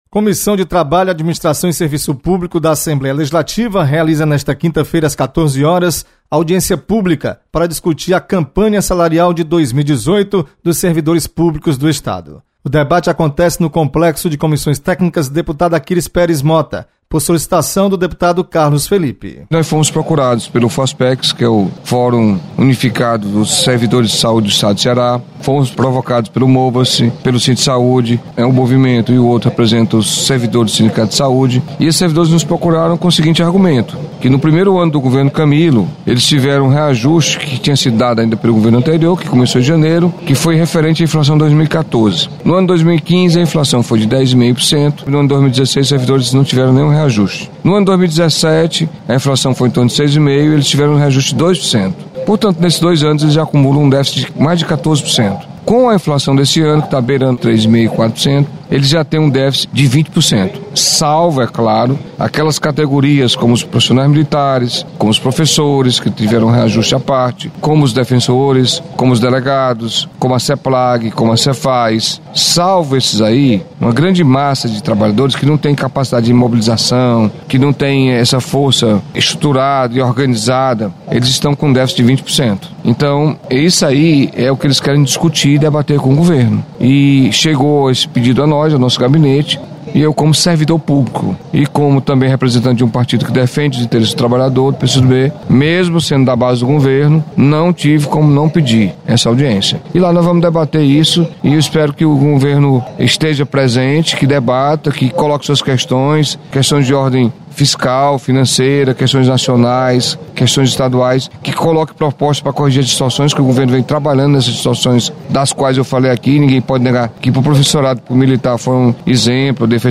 Comissão de Trabalho Administração e Serviço Público realiza audiência reunião nesta quinta-feira. Repórter